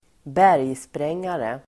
Uttal: [²b'är:jspreng:are]